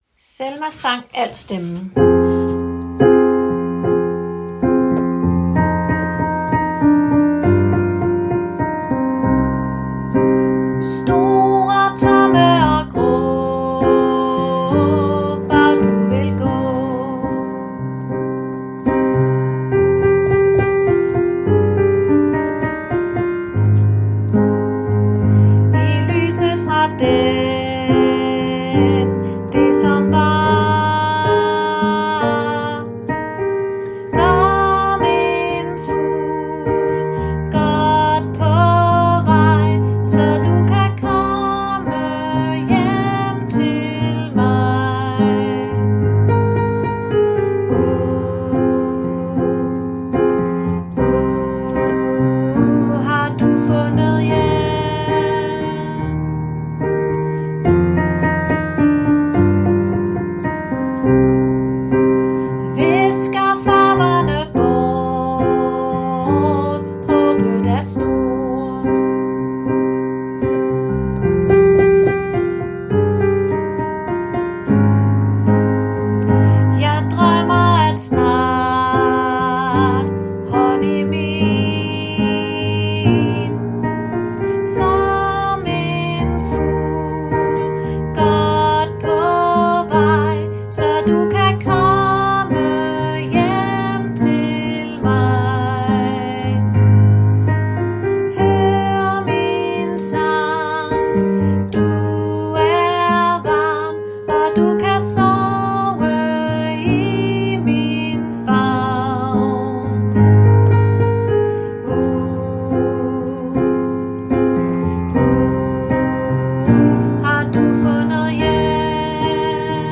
Selmas sang – alt
Selmas_sang_alt.m4a